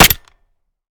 Home gmod sound weapons papa320
weap_papa320_fire_last_plr_mech_02.ogg